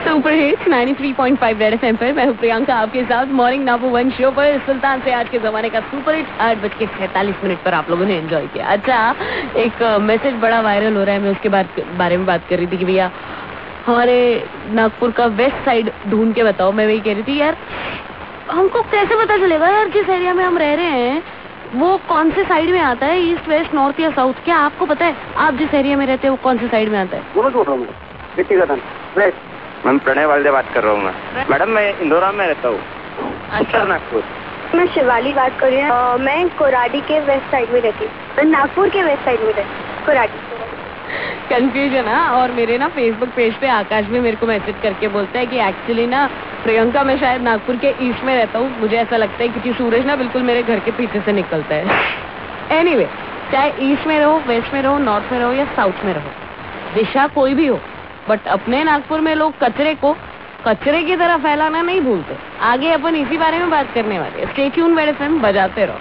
27JUL_L8_CALLER INTERACTION